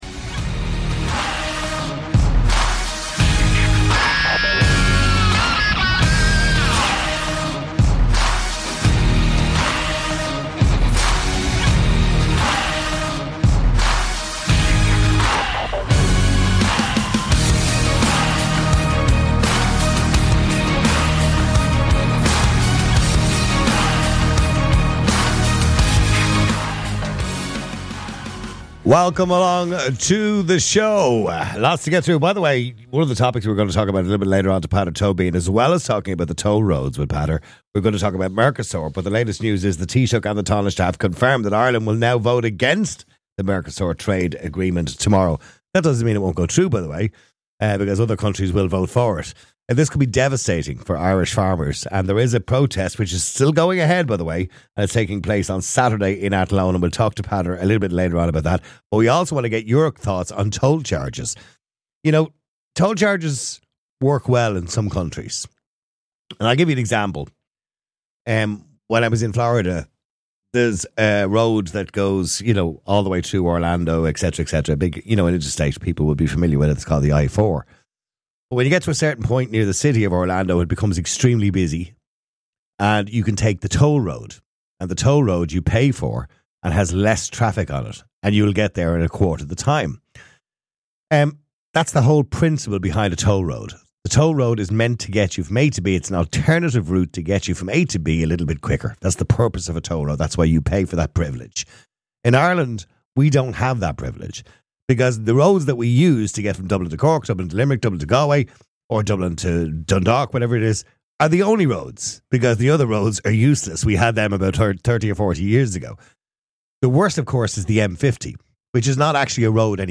Some callers are critical of the government's priorities.